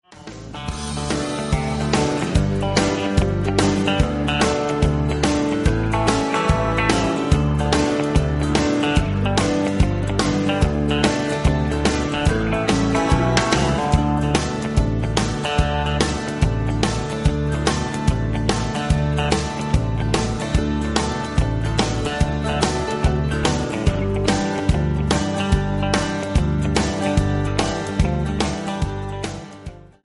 MP3 – Original Key – Backing Vocals Like Original
Pop